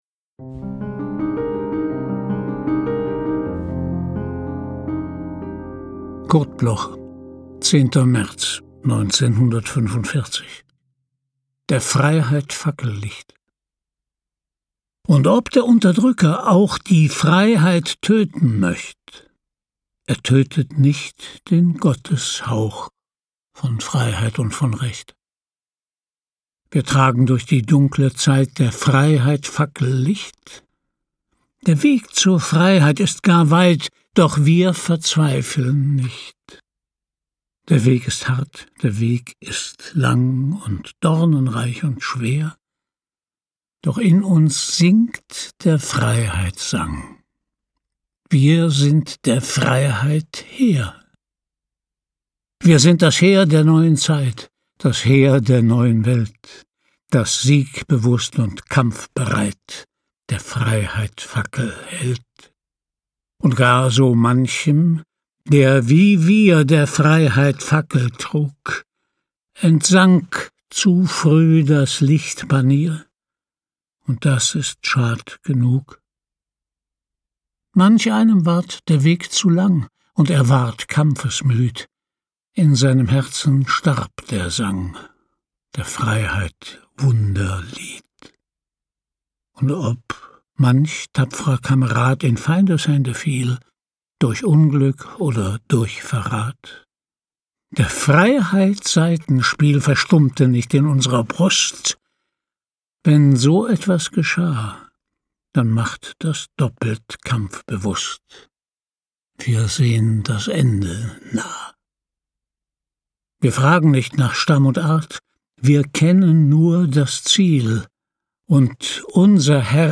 Aufnahme: studio_wort, Berlin · Bearbeitung: Kristen & Schmidt, Wiesbaden
Christian-Brueckner-Der-Freiheit-Fackellicht-mit-Musik.m4a